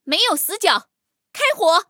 黑豹夜战攻击语音.OGG